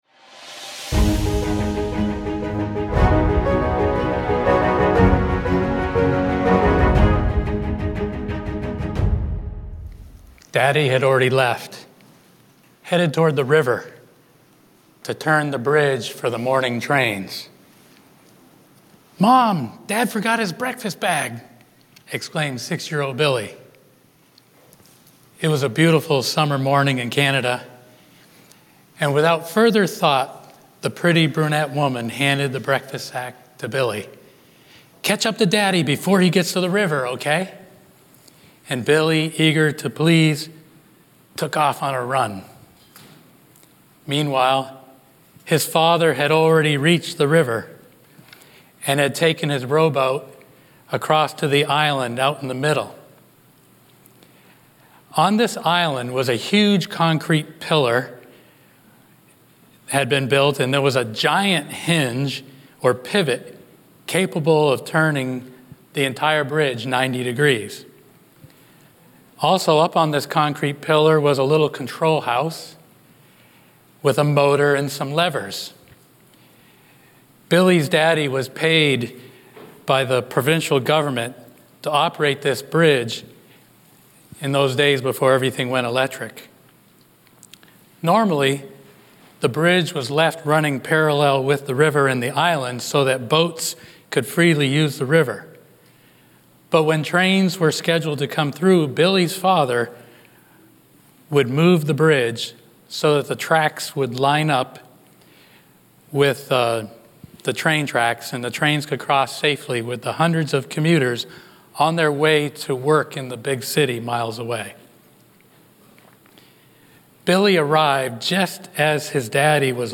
A message from the series "Central Sermons."
From Series: "Central Sermons"